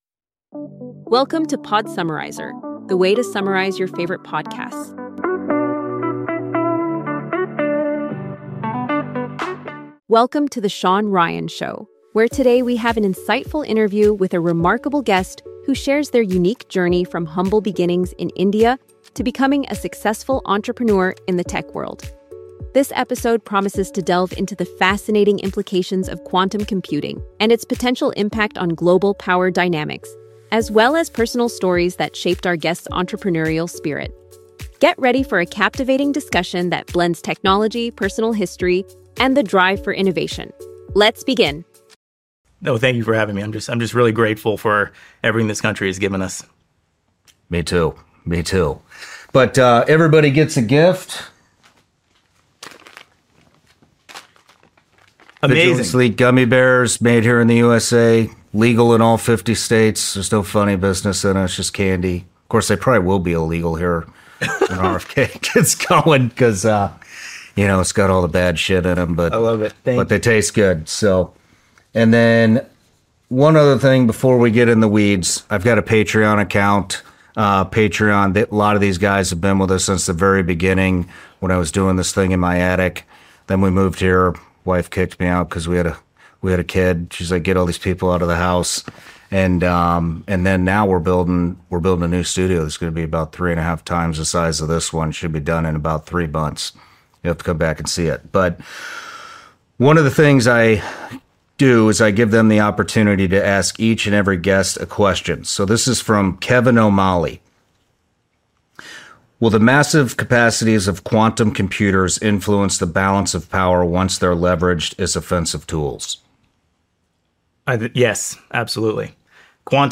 In this engaging podcast episode, the host interviews a tech entrepreneur who discusses the transformative impact of quantum computing and AI on national security and warfare. They explore how these technologies can enhance decision-making processes, streamline military operations, and improve data integration across various defense sectors. The conversation also touches on the importance of fostering a culture of innovation within the military, the challenges posed by adversaries like China, and the need for a more agile and responsive defense acquisition process.